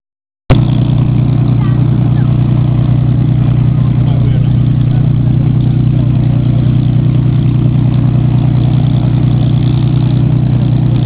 Spitfire warming up for the 9am display - an enthusiastic sound recordist is alongside the fence to capture the wonderful sound the Merlin makes as the engine warms up. We have a short audio clip from a digital camera to give you a flavour!
Merlin warming up - audio clip
Merlin warming up.WAV